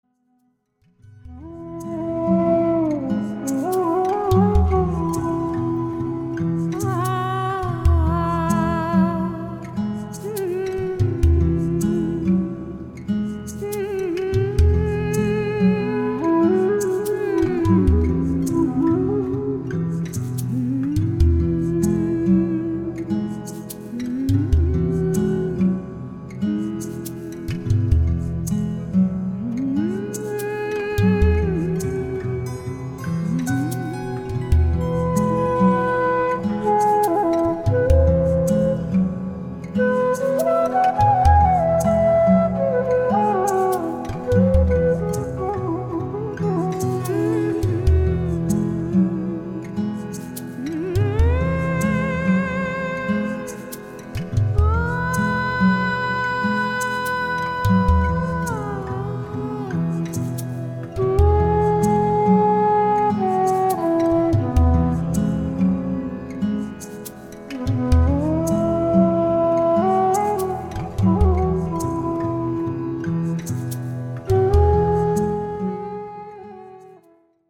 Genre: World Fusion.
bansuri and swarmandal
vocals
udu drums and shakers
guitar